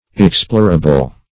Explorable \Ex*plor"a*ble\, a. That may be explored; as, an explorable region.